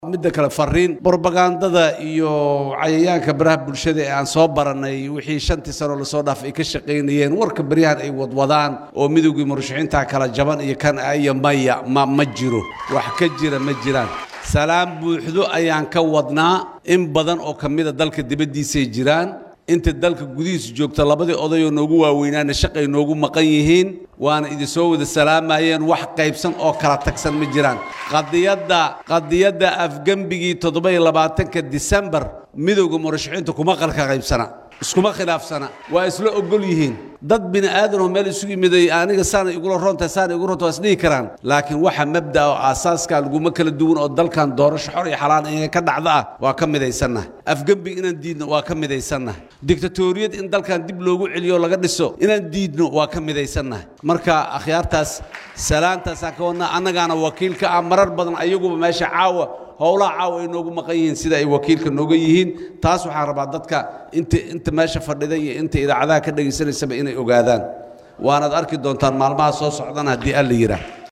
Xasan Sheekh oo ka hadlayay kulan ay Midowga Musharrixiinta ku yeesheen magaalada Muqdisho ayaa xusay in warka sheegaya inay kala qaybsan yihiin aysan waxba ka jirin, islamarkaana ay yihiin warar been abuur ah oo ay soo abaabuleen sida uu hadalkiisa u dhigay dad dhaqaale lagu siiyo wararka noocaan ah.